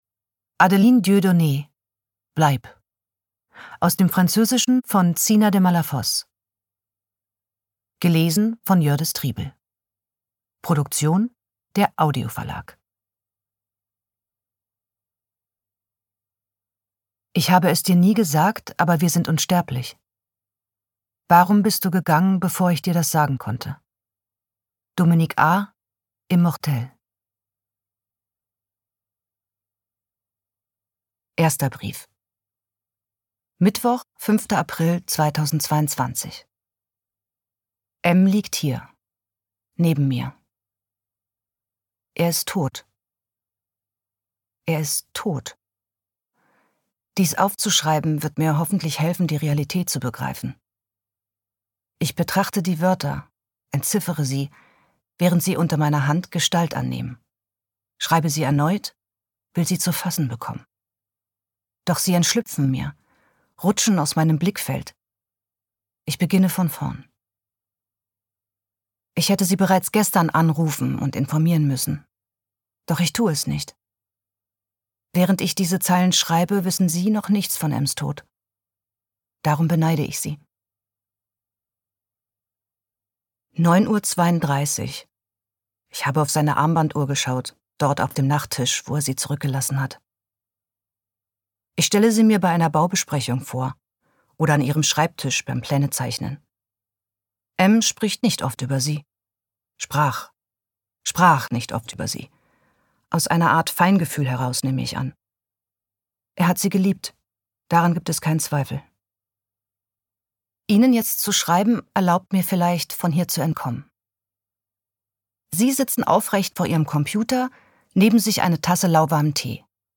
Ungekürzte Lesung mit Jördis Triebel (1 mp3-CD)
Jördis Triebel (Sprecher)